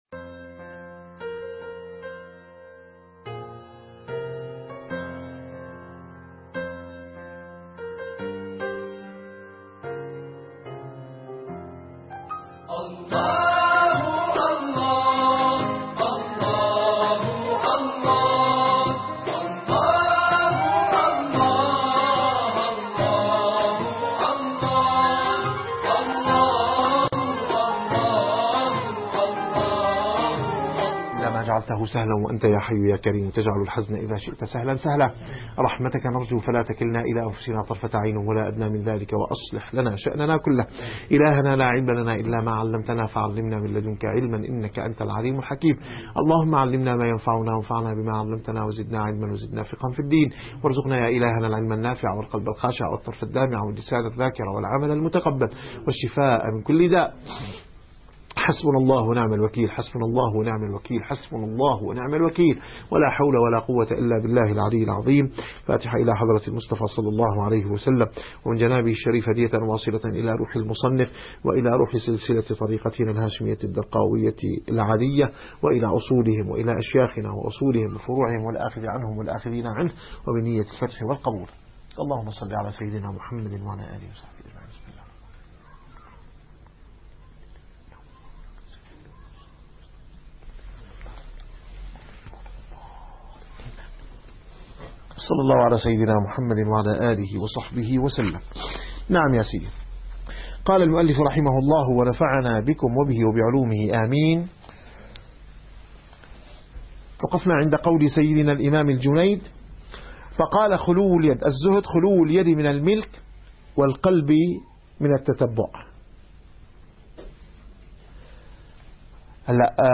- الدروس العلمية - الرسالة القشيرية - الرسالة القشيرية / الدرس الواحد والثمانون.